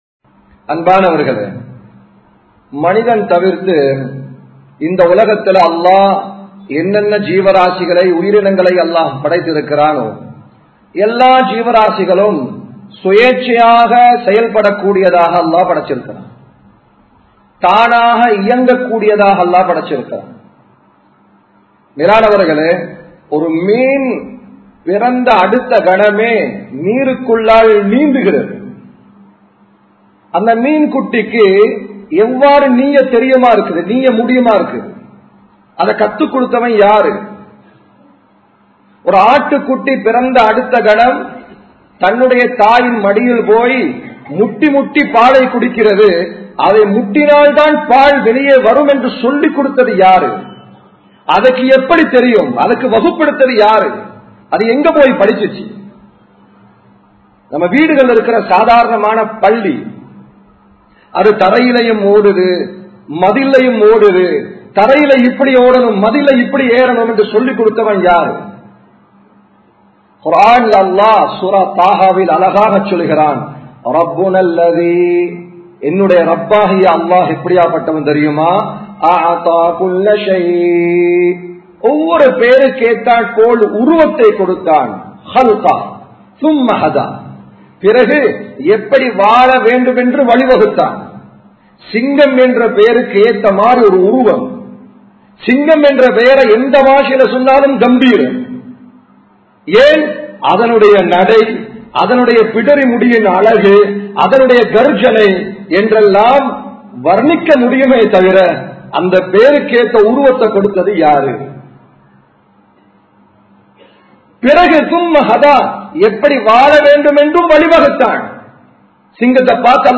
முஸ்மினின் பண்புகள் | Audio Bayans | All Ceylon Muslim Youth Community | Addalaichenai
Samman Kottu Jumua Masjith (Red Masjith)